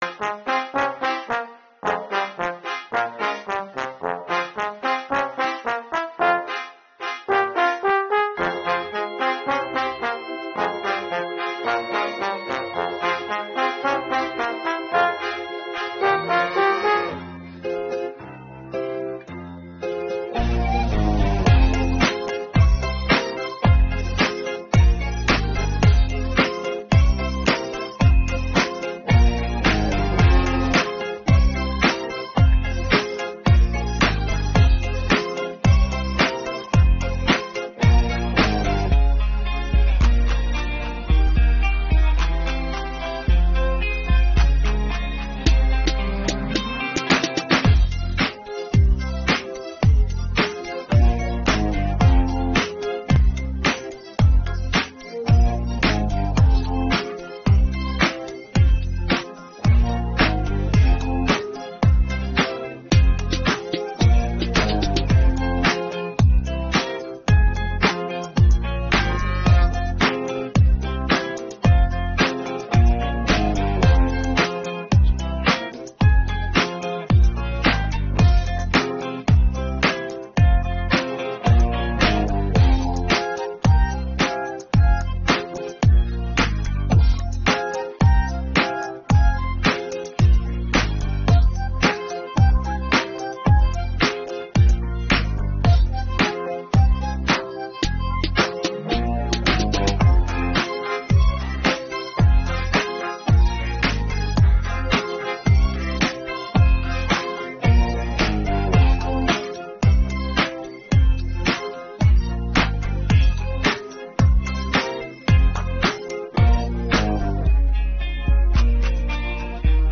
Українські хіти караоке Описание